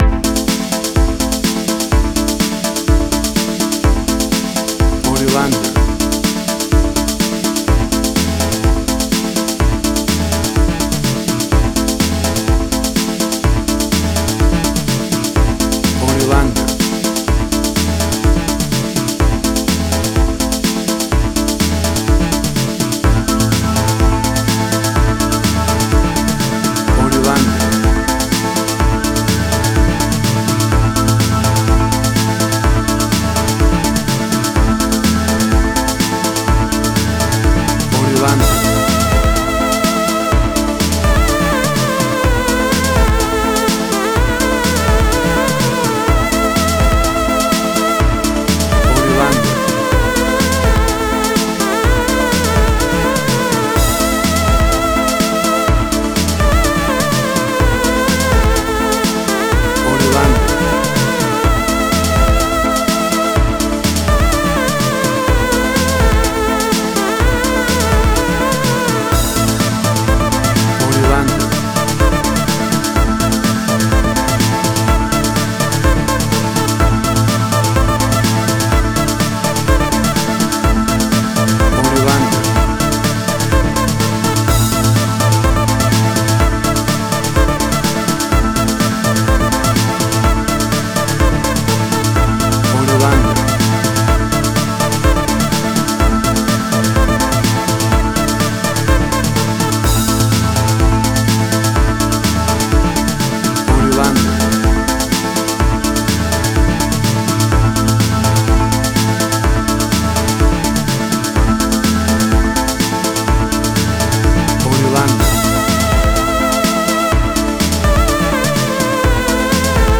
Tempo (BPM): 125